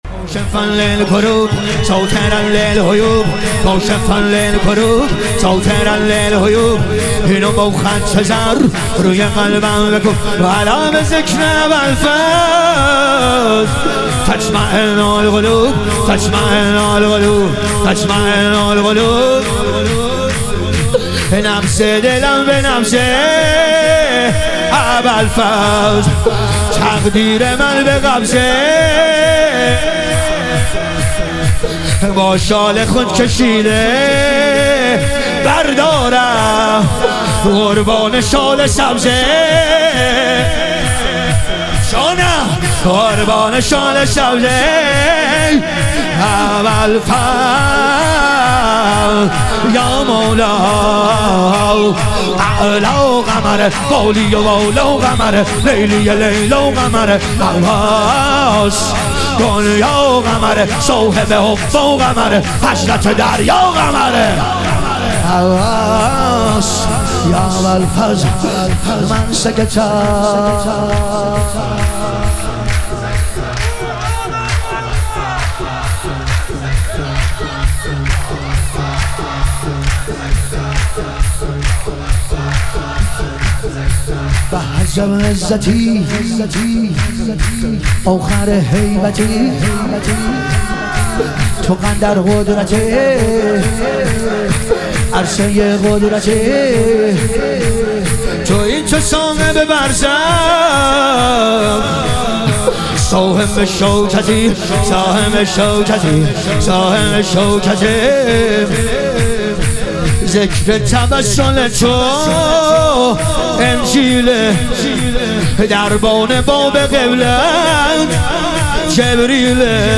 ظهور وجود مقدس امام سجاد علیه السلام - شور